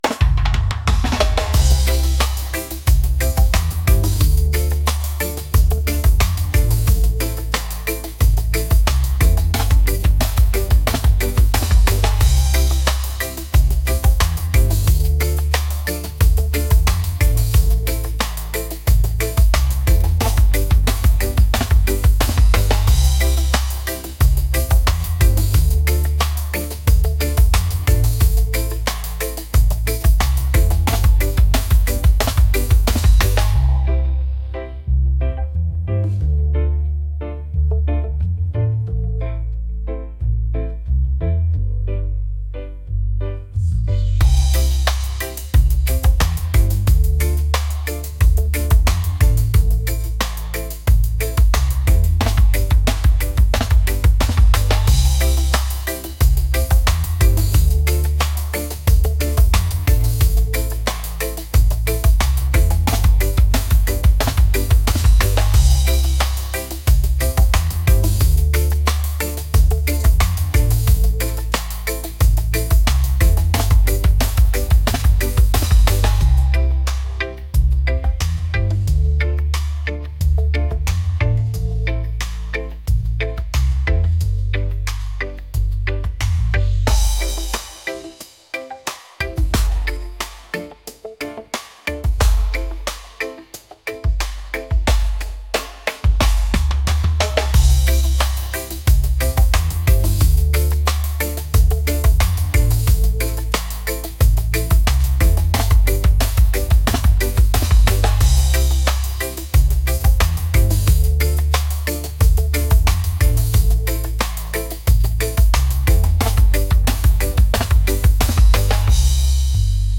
reggae | energetic | upbeat